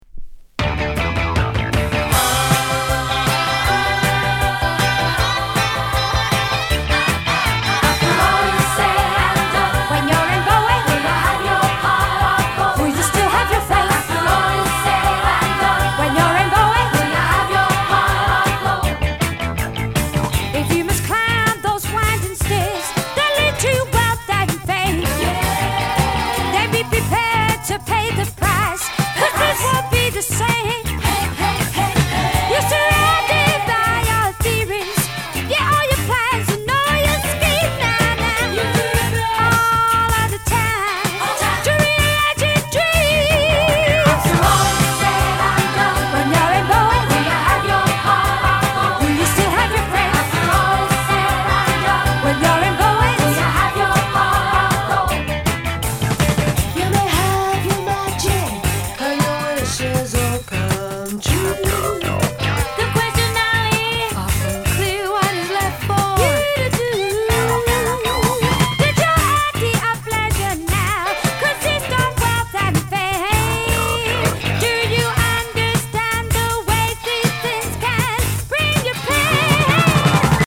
Genre:  Soul /Funk